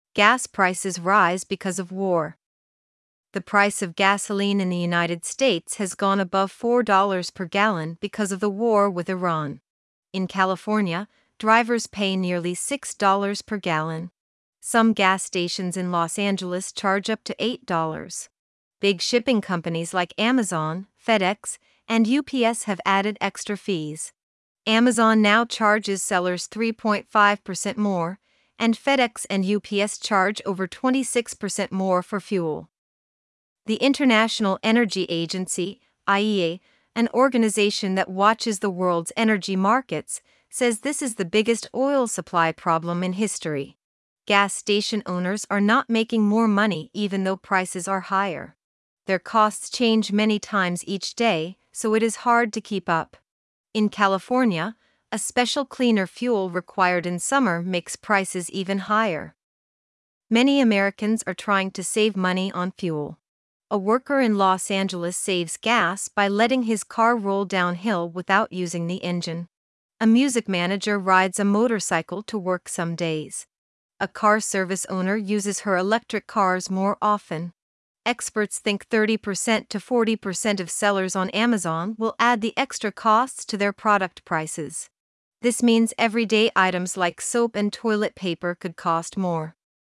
3. 使用 Azure TTS 来生成英文音频 + 字幕
已经重新调优了 TTS 参数：换了更适合朗读的 Azure 声音（ Aria ），音质从 16Khz 升到 24Khz ，并且给不同难度加了不同的说话风格——初级（ A1/A2 ）用亲切语气、中高级（ B1+）用新闻播报风格，句间停顿也按级别做了区分。
2. 把新版的 TTS 发单上线，声音更带感